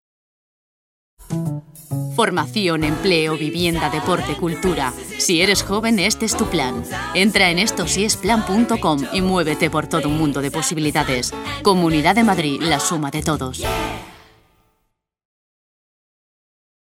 Voz cálida y expresiva.
kastilisch
Sprechprobe: Werbung (Muttersprache):